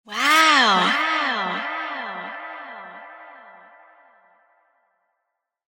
Sound Effects Library. Wow Sound Effect (Anime Style) (CC BY)
sound-effects-library-wow-sound-effect-anime-style.mp3